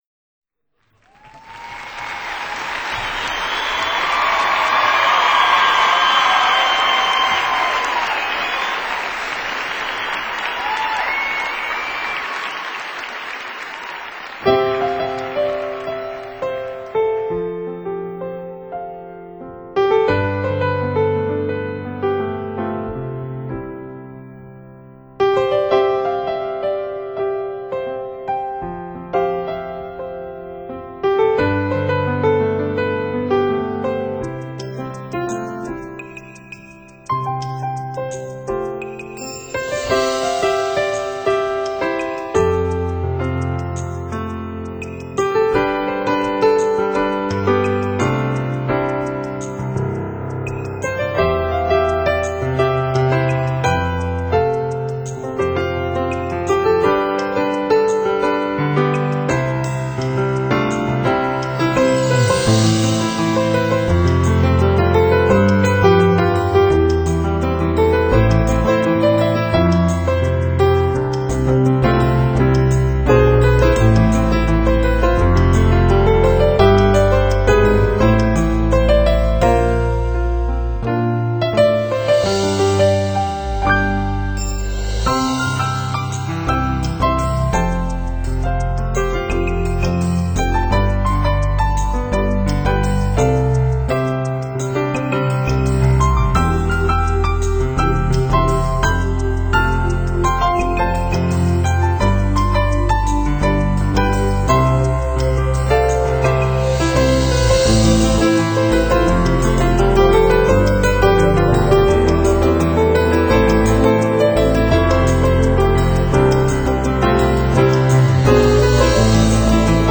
专辑主要是在2000年3月的盐湖城音乐会上现场录制的，再一次显示了他不一般的艺术天份。